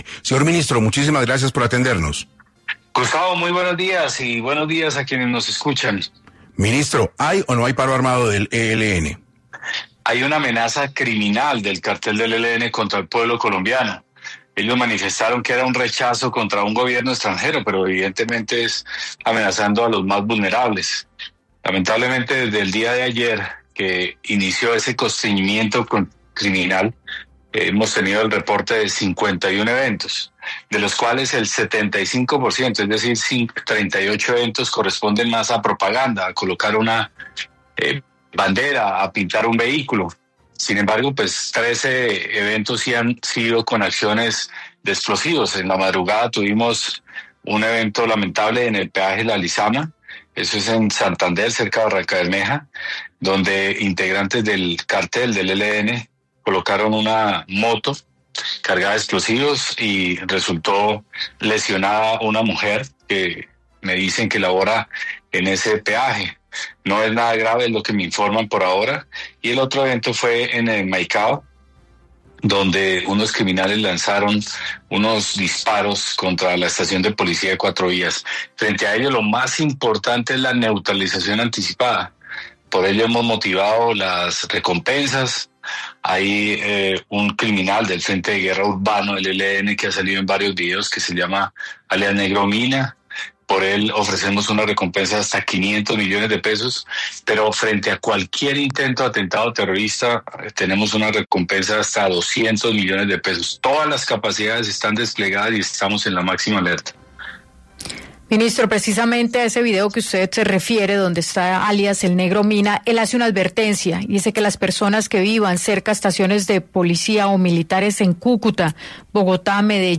El ministro de Defensa habló en 6AM de la situación de orden público en Colombia